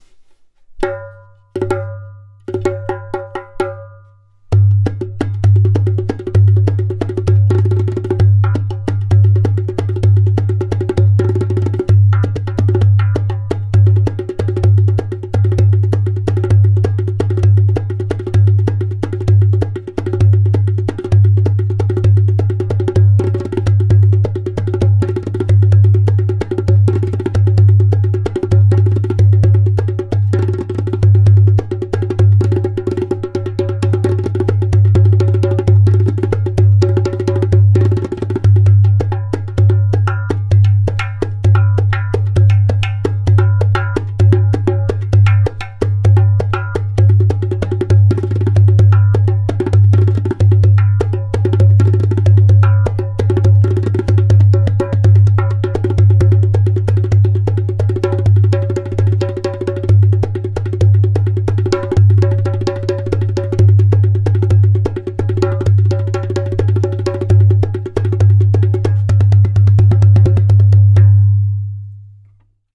130 bpm:
In this line of darbukas materials like clay, glaze and natural skin met in a magical way which brings into life a balanced harmonic sound.
• Taks with harmonious overtones.
• Deep bass.
• Loud clay kik/click sound!